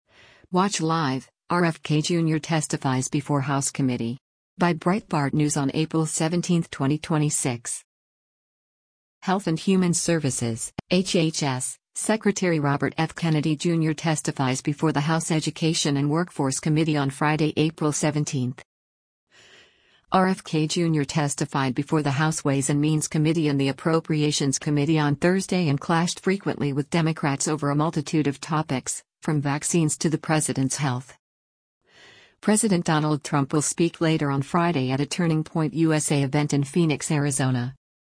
Health and Human Services (HHS) Secretary Robert F. Kennedy Jr. testifies before the House Education and Workforce Committee on Friday, April 17.